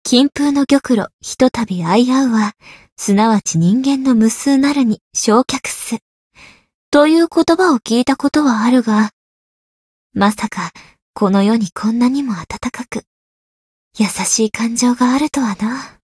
灵魂潮汐-蕖灵-七夕（摸头语音）.ogg